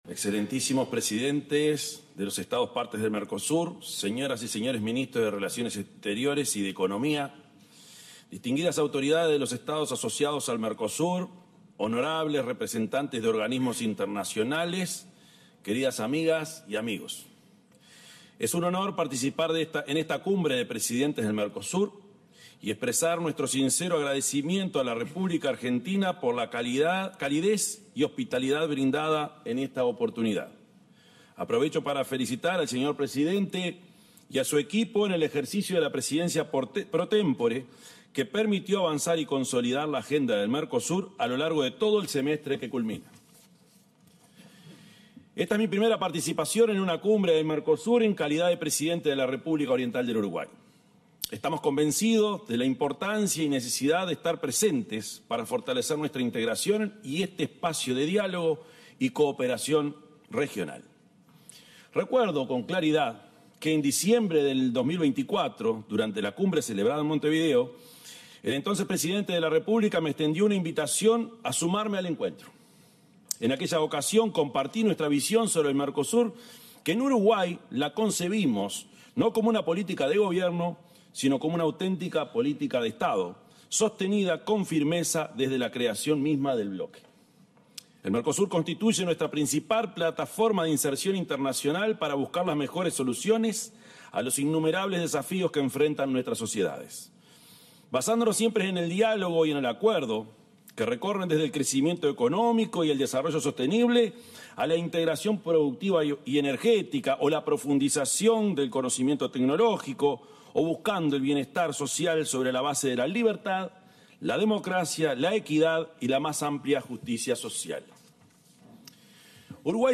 El presidente de la República, profesor Yamandú Orsi, disertó durante la sesión plenaria de la Cumbre de Presidentes del Mercosur, en Buenos Aires.